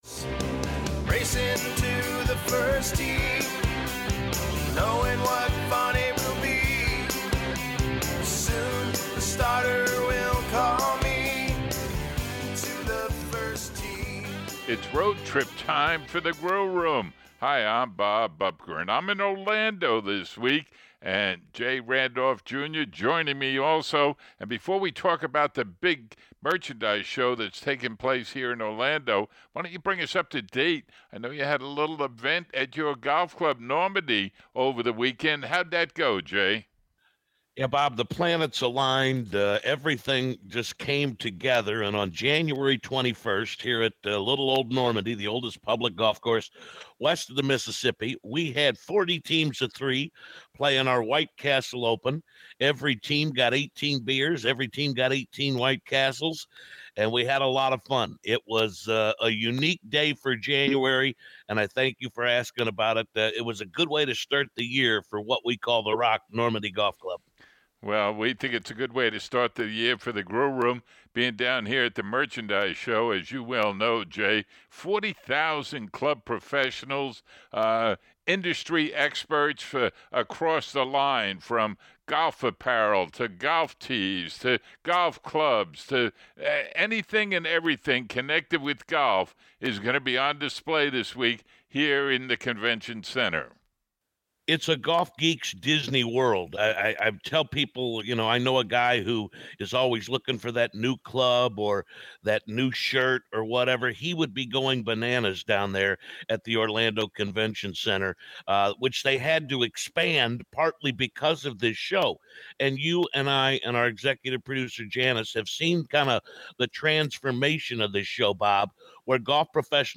Feature Interview: John Daly talks about his Vertical Groove Driver and the impact it has on his game. The Grill Room is on a road trip to the 64th PGA Merchandise Show in Orlando where new golf products make the news. Other items: Re-cap of Career Builder Challenge, Preview of Farmer's Insurance Open. News & Notes: Tiger's new equipment & USGA to change the rules of golf.